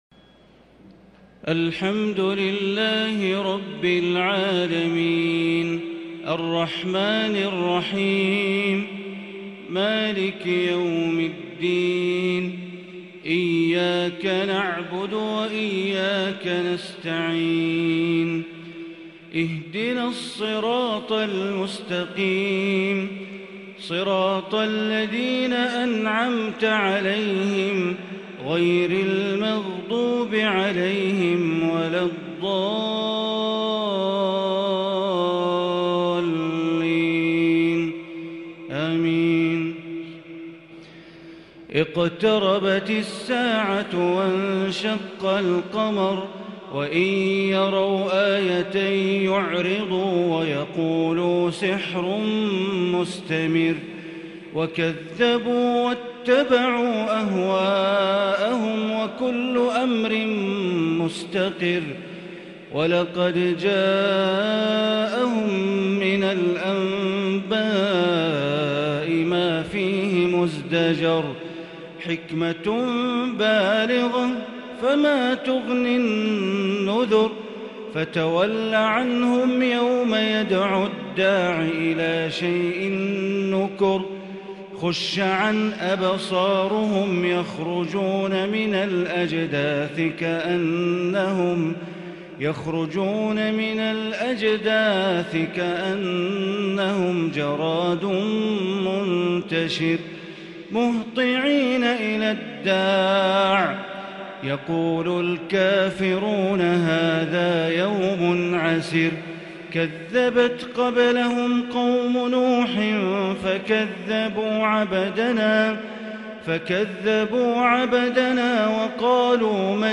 صلاة التراويح l ليلة 28 رمضان 1442 | سور القمر والرحمن والواقعة | taraweeh prayer The 28th night of Ramadan 1442H | > تراويح الحرم المكي عام 1442 🕋 > التراويح - تلاوات الحرمين